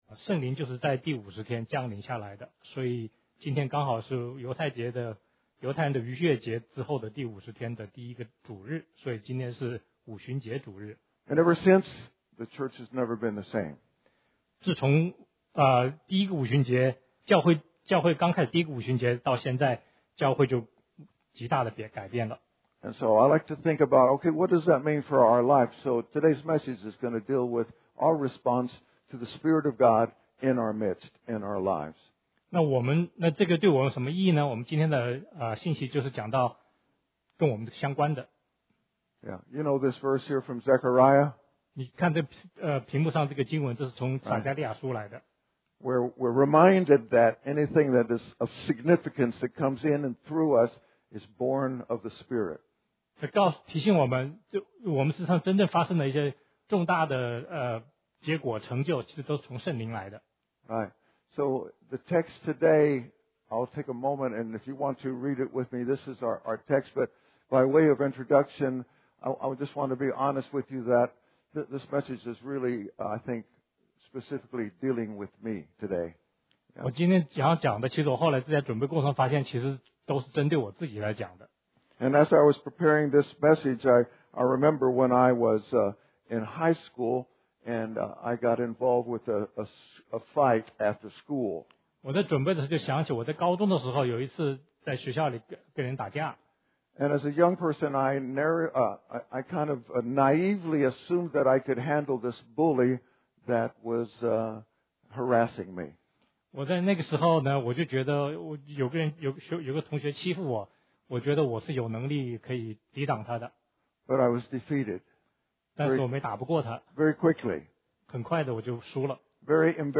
The Possibilities & Power of Faithfulness & believing Prayer_Mark_9.14-29_250608 Audio Type: English Sermon